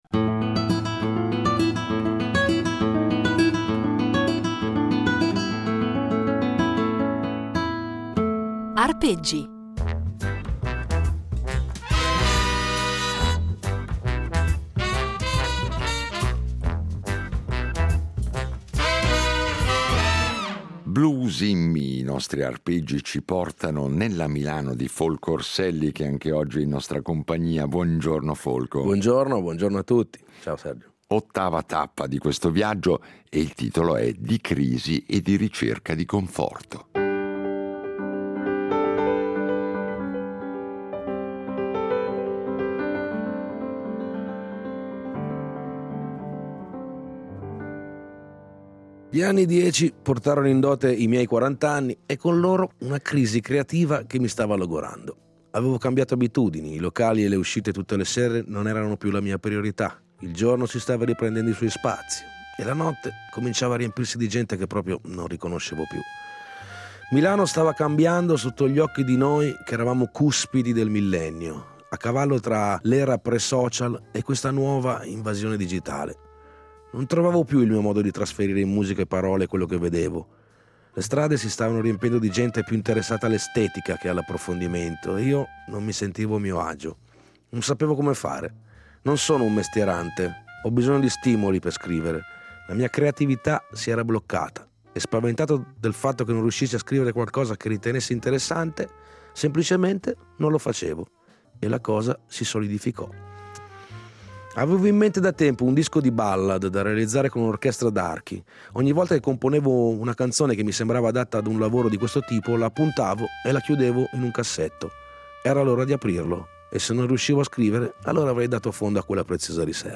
Un itinerario impreziosito, in ogni puntata, da un brano eseguito solo per noi, ai nostri microfoni.